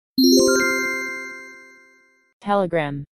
Звук уведомления для телеграма